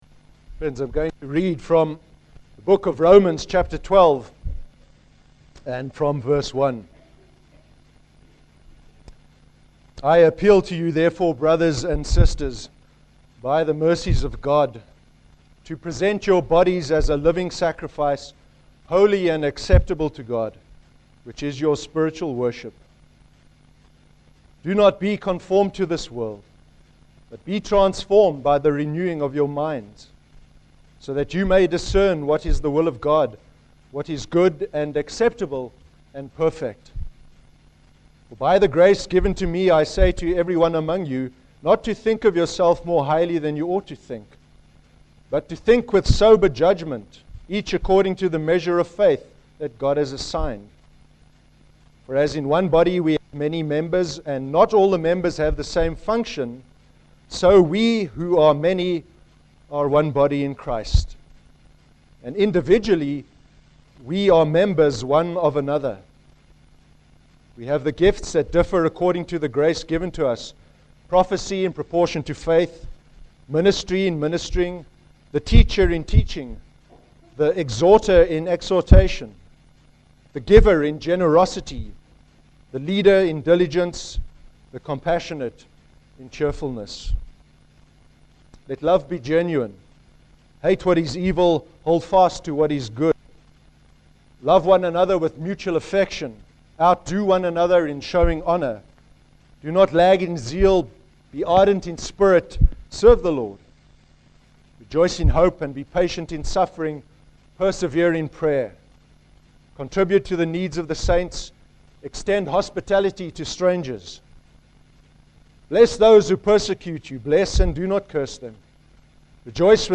31/03/13 sermon – Easter Sunday service (Romans 12:1-21)